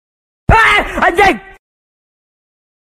Efek Suara Aaa Anjing
Kategori: Suara viral
Keterangan: Sound Effect "Aaa Anjing" adalah suara meme yang viral digunakan dalam video lucu dan editan. Cocok untuk reaksi kejutan atau lucu.